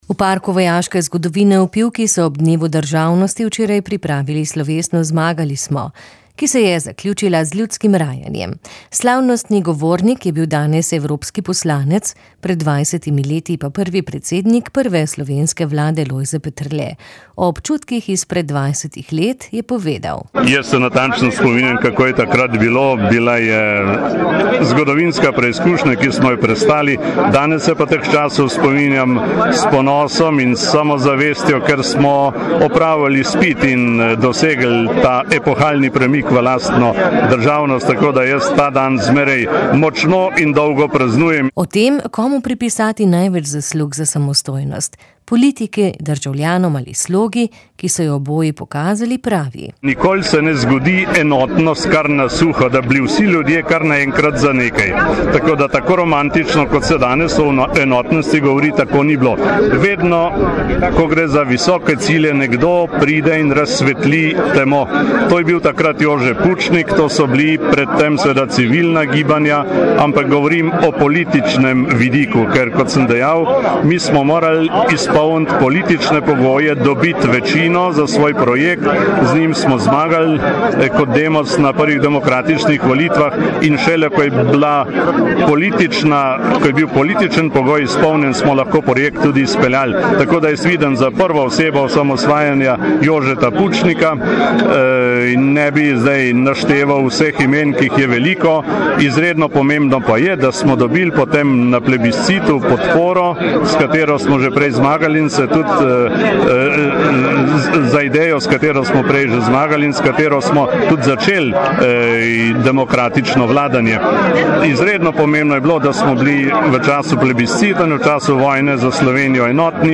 Evropski poslanec Lojze Peterle je v javljanju iz evropskega parlamenta v Bruslju spregovori o sprejemanju tobačne direktive, gendercidu in o svojem predavanju o slovenski tranziciji.